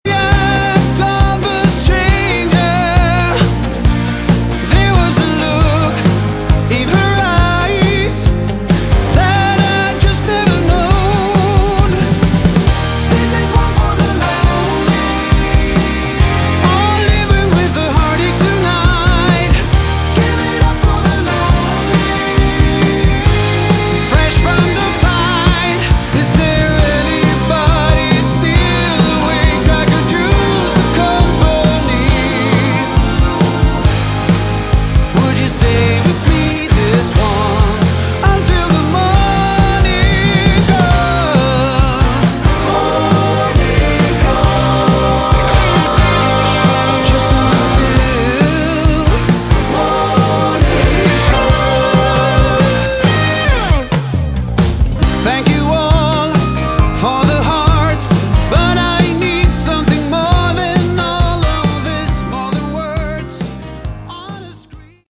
Category: AOR
Vocals
Guitars
Bass
Keyboards
Drums